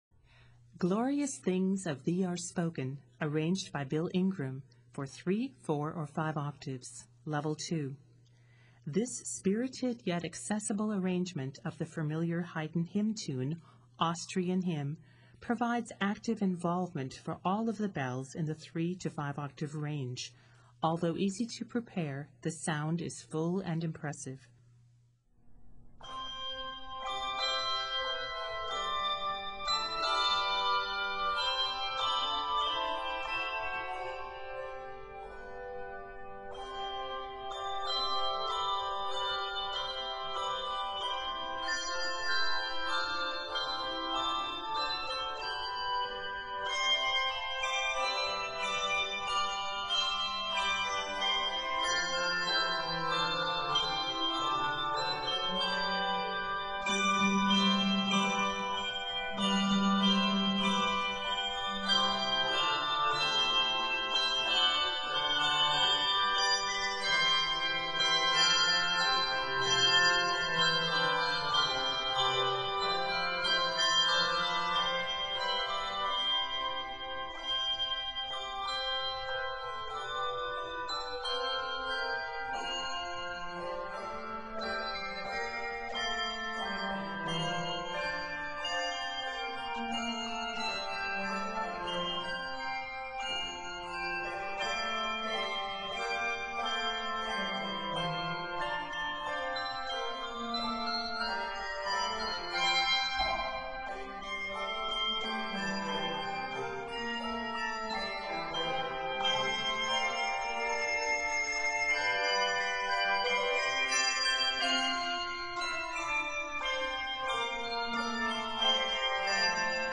This spirited yet accessible arrangement